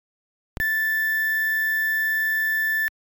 Are you seeing any pattern in the resulting waveforms as we increase filter cutoff?
cutoff40.mp3